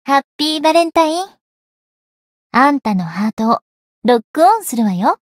灵魂潮汐-星见亚砂-情人节（相伴语音）.ogg